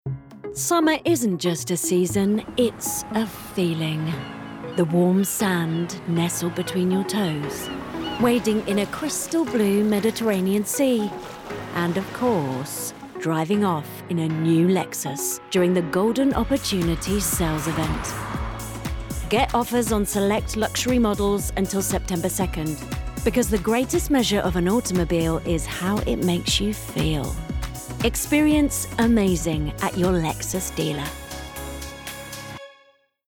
new commercial showreel!
40's Neutral/London, Warm/Reassuring/Calm